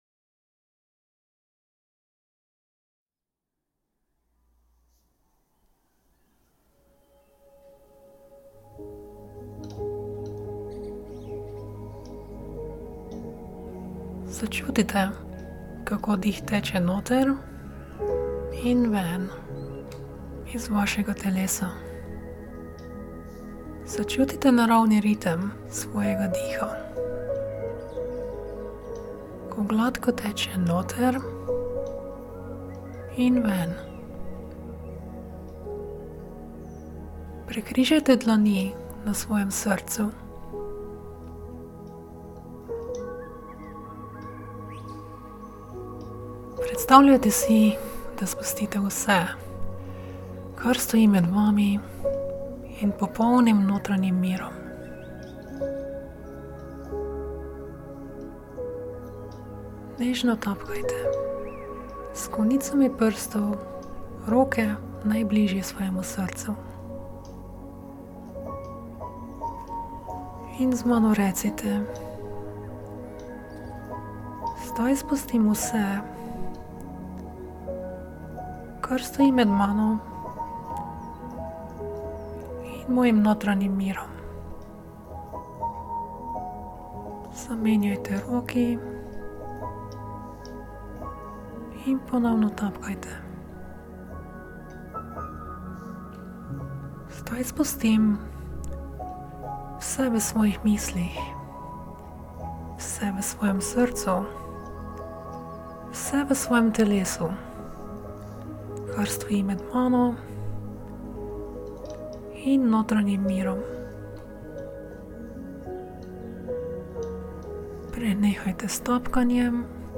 Vodena meditacija, ki začetnikom dokazano pomaga doseči podobne možganske vzorce kot mojstri zena.
EkoMeditacija je ena izmed oblik vodene meditacije, ki začetnikom dokazano pomaga v možganih prebuditi podobne vzorce, kot jih lahko izmerimo v mojstrih zena.